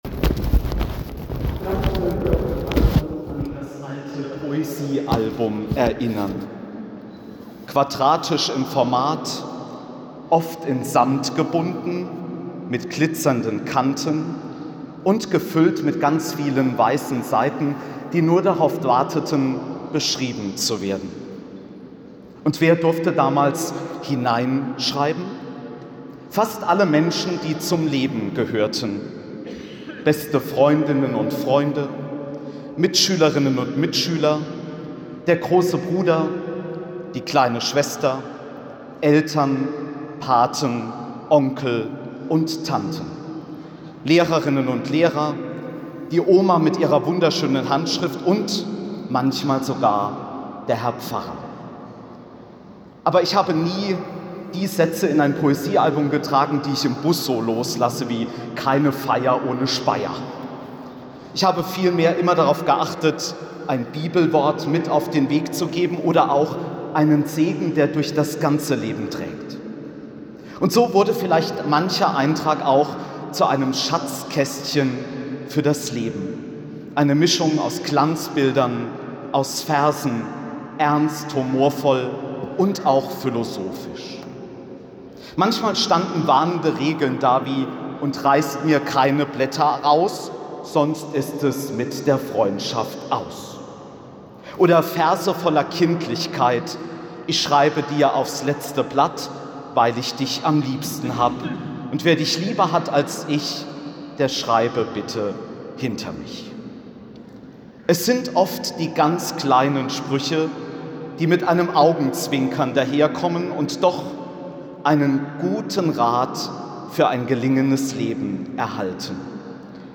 Predigt von Erzbischof Dr. Udo Bentz in St. Paul vor den Mauern